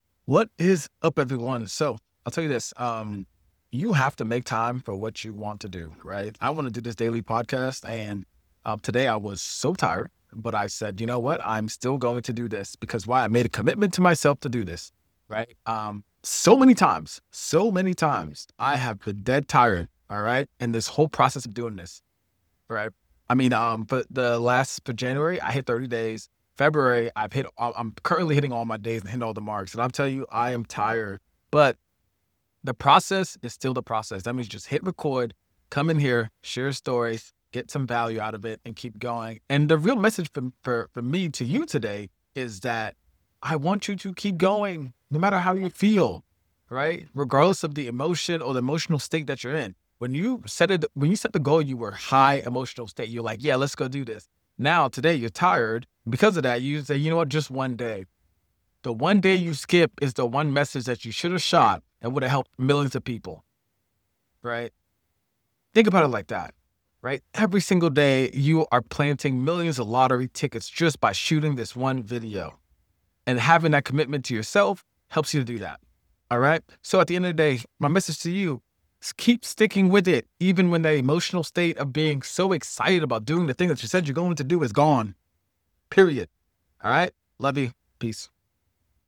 In this episode, I’m coming to you tired — really tired — but committed.